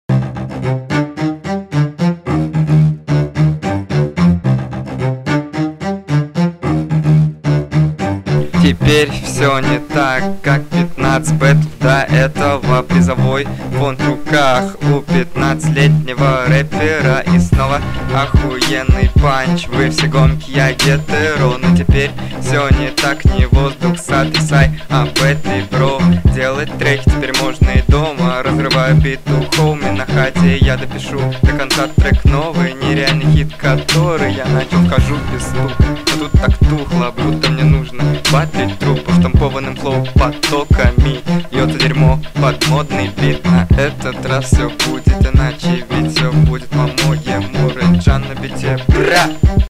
Разговариваешь под музыку на избитые темы